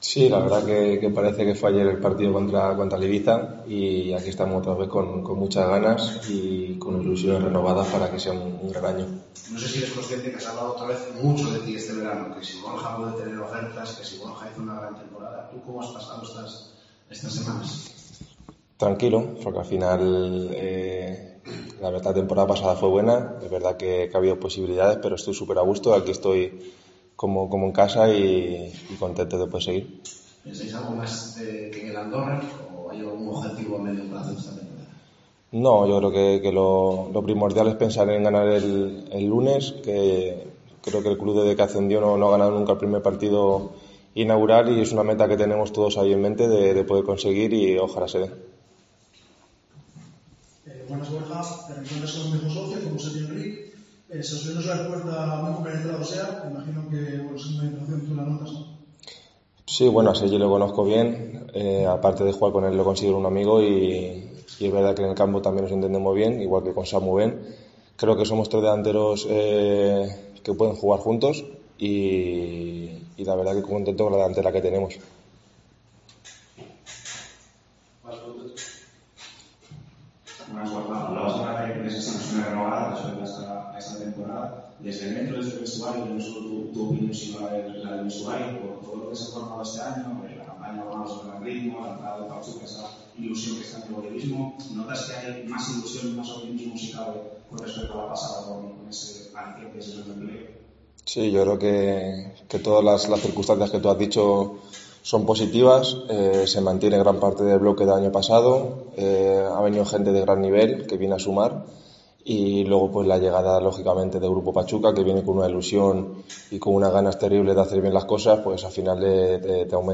Rueda de prensa de Borja Bastón